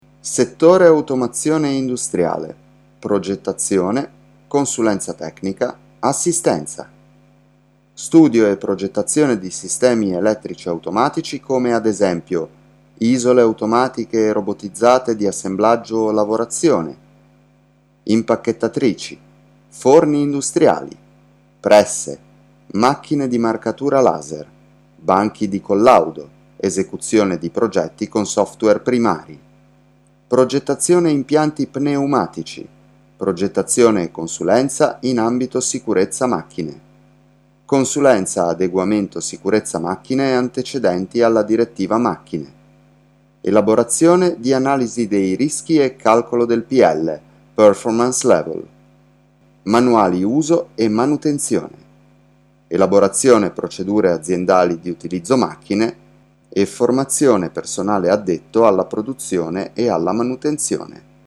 I can change my voice in various ways, making it younger, older, bad, good, sharp, rough, and so on.
Sprechprobe: Industrie (Muttersprache):